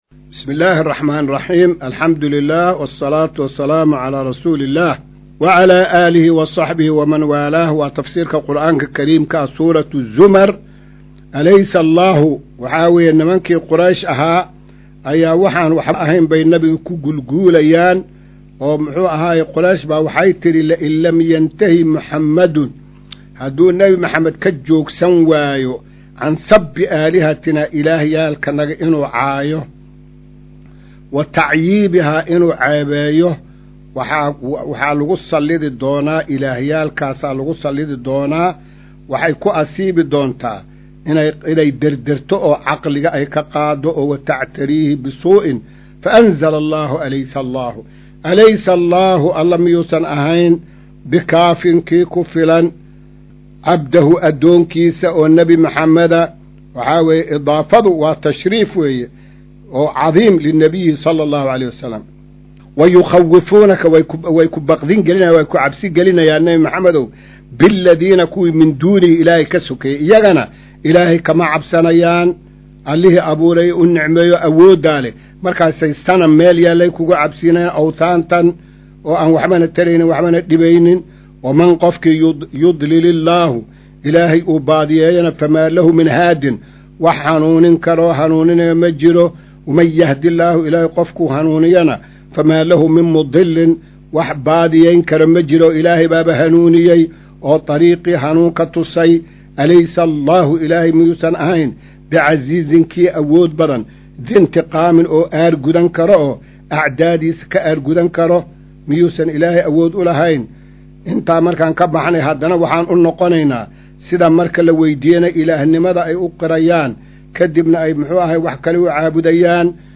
Maqal:- Casharka Tafsiirka Qur’aanka Idaacadda Himilo “Darsiga 220aad”